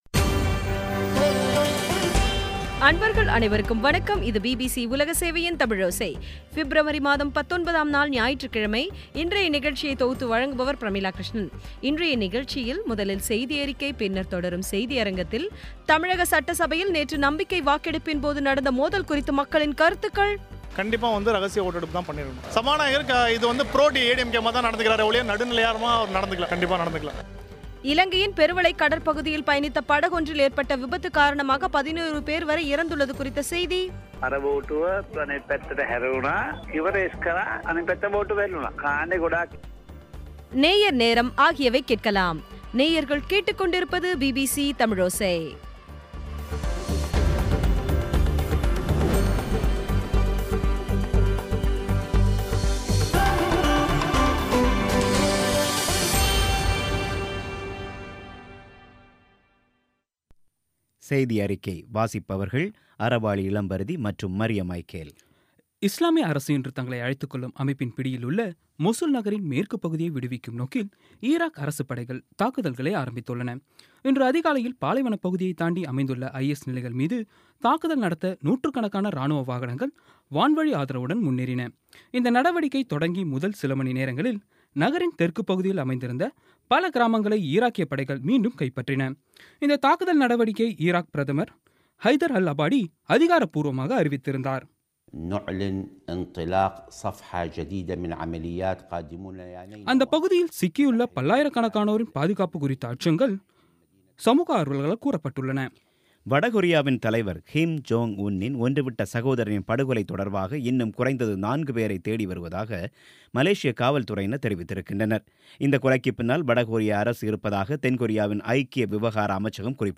இன்றைய நிகழ்ச்சியில் முதலில் செய்தியறிக்கை, பின்னர் தொடரும் செய்தியரங்கில் தமிழக சட்டசபையில் நேற்று நம்பிக்கை வாக்கெடுப்பின் போது நடந்த மோதல் குறித்து மக்களின் கருத்துக்கள் இலங்கையின் பேருவளை கடற்பகுதியில் பயணித்த படகொன்றில் ஏற்பட்ட விபத்து காரணமாக 11 பேர் வரை இறந்துள்ளது குறித்த செய்தி நேயர் நேரம் ஆகியவை கேட்கலாம்